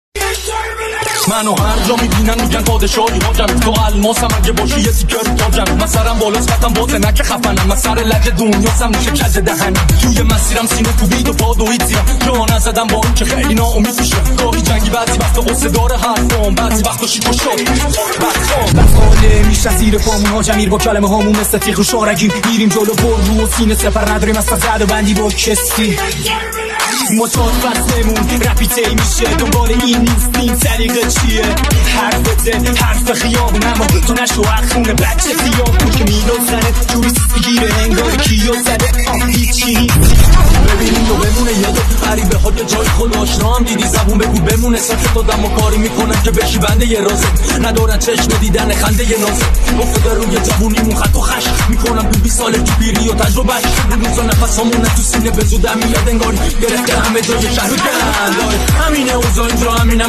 دانلود اهنگ به فرزندان خود قاچاق یاد بدید لاتی معروف